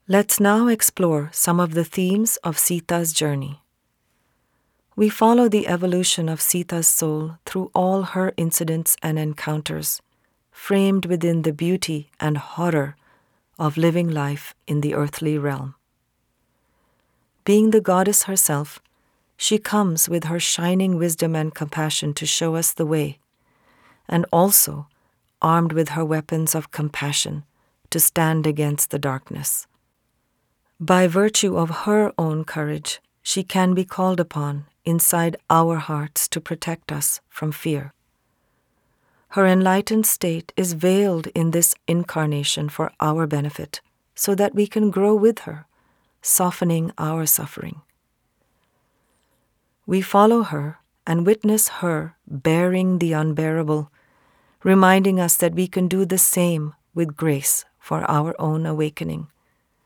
part audiobook, part guided course